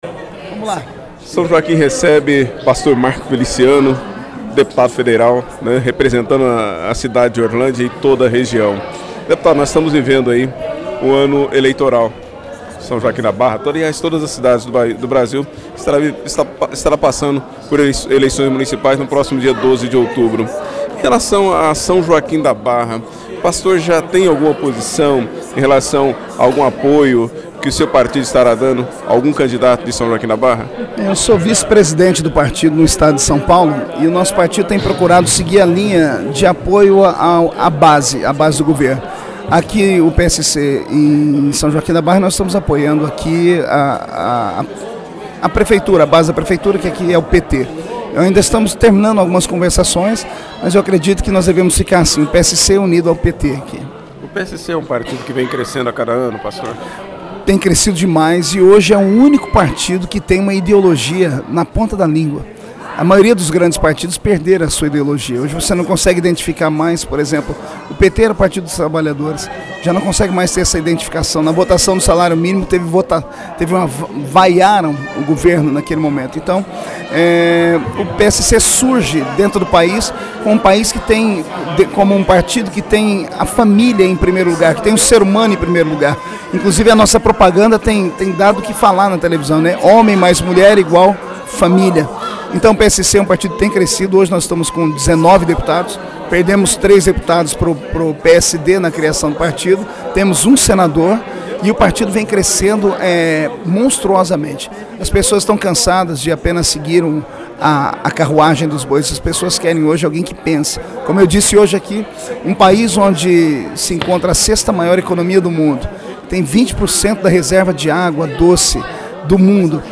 Em entrevista a rádio Lidersom FM durante sua visita à São Joaquim da Barra, o Deputado Federal Pastor Marco Feliciano disse o que...
entrevista-marco-feliciano.mp3